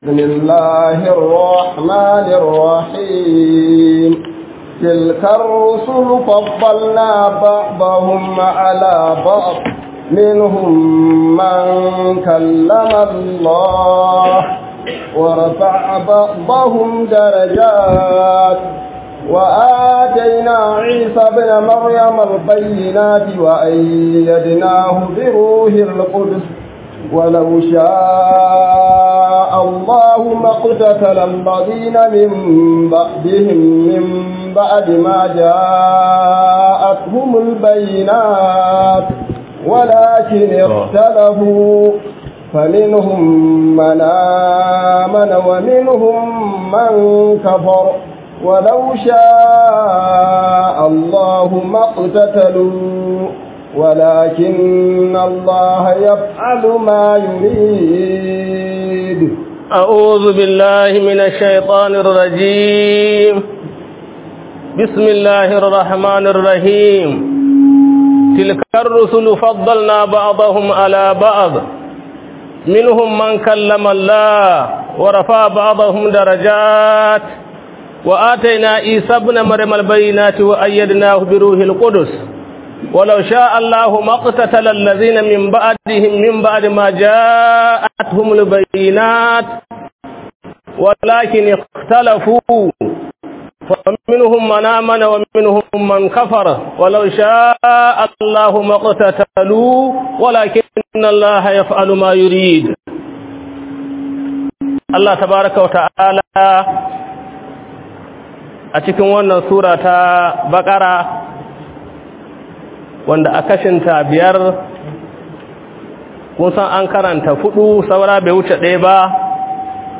Fityanumedia Audios is a platform dedicated to sharing audio files of lectures from renowned Islamic scholars.
027A_Tafsir_Ramadan.mp3